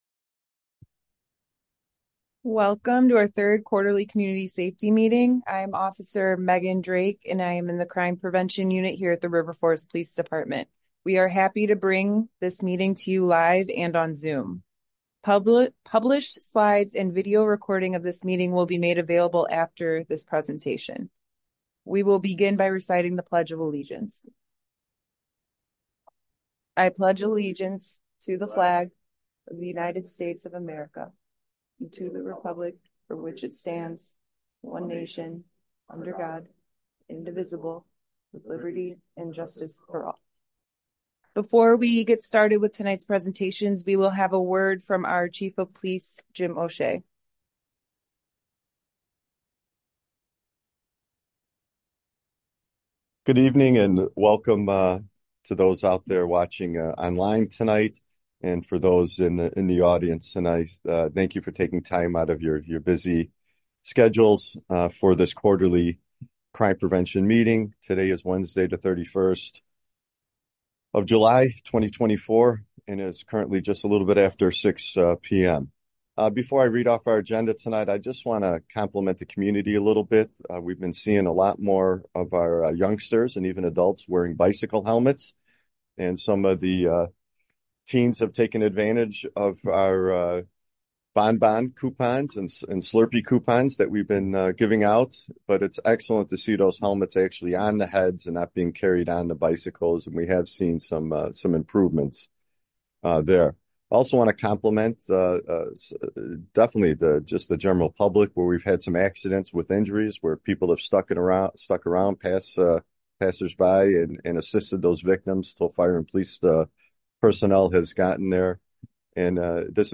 Village Hall - 1st Floor - COMMUNITY ROOM - 400 Park Avenue - River Forest
We will have presentations from special guest community partners as well as members of our own police department. The meeting will conclude with an open forum for community member questions, comments, and concerns.